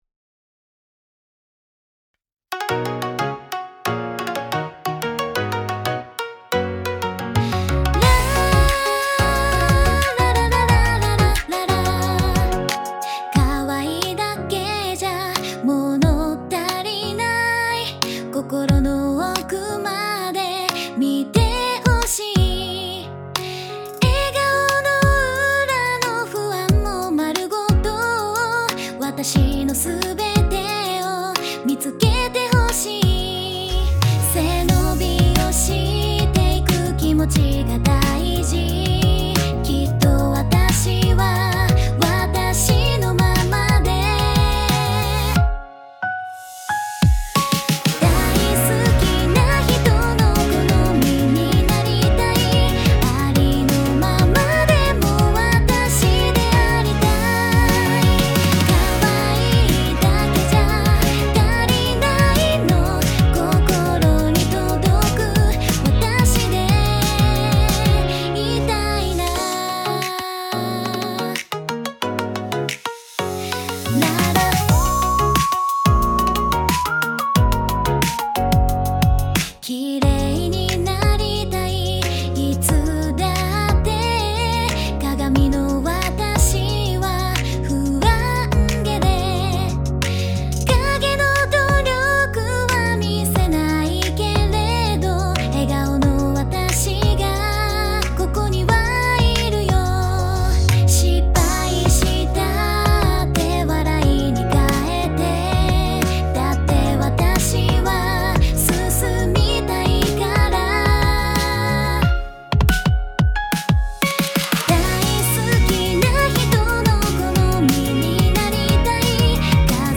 かわいいアイドルソング(限界)もできたところで
BPM 90 (180)
これが私の限界カワイイみたいです😭難しい！